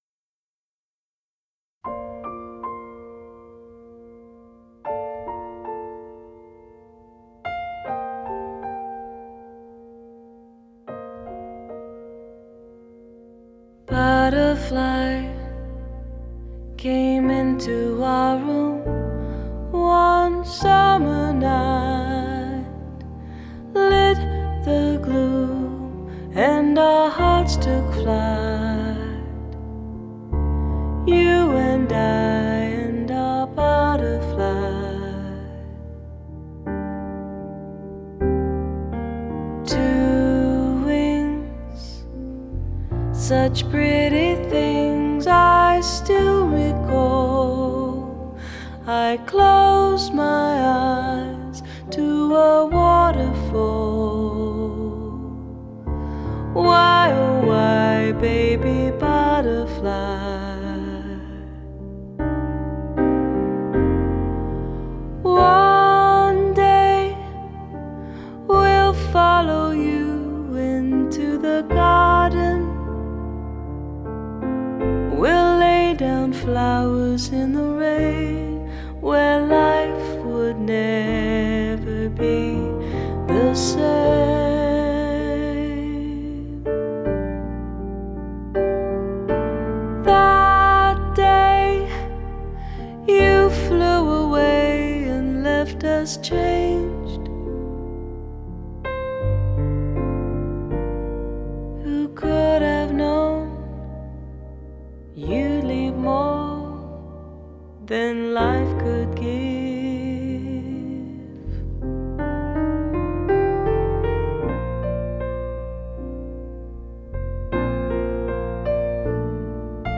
молодую британскую певицу
поп-музыка